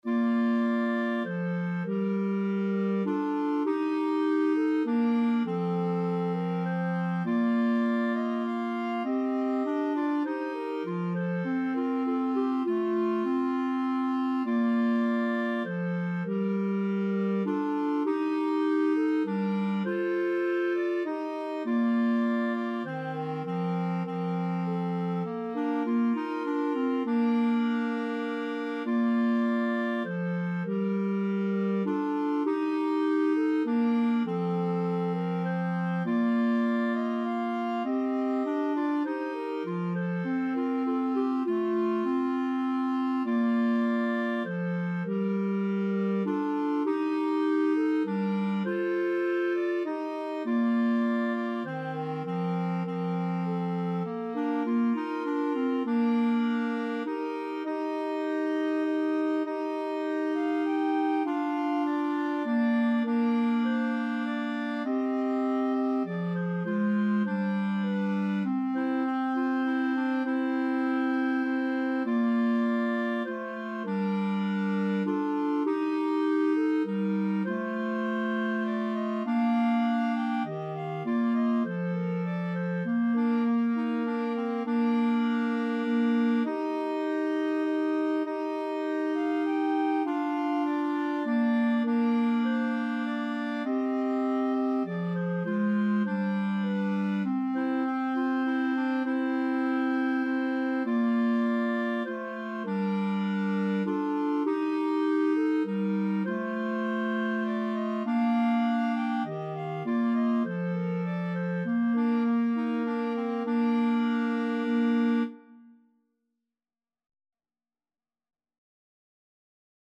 Bb major (Sounding Pitch) C major (Clarinet in Bb) (View more Bb major Music for Clarinet Trio )
3/4 (View more 3/4 Music)
Clarinet Trio  (View more Intermediate Clarinet Trio Music)
Classical (View more Classical Clarinet Trio Music)